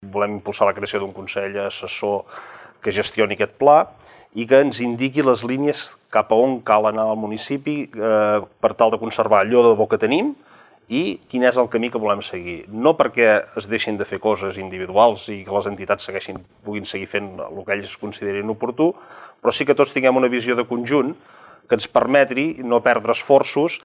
Debat Electoral Torroella de Montgrí - l'Estartit 2019
Un dia en què l’agenda vindrà marcada per les propostes que es van llançar ahir des de l’estudi a l’hotel Mas de Torrent des d’on cada vespre emetem un dels nostres debats.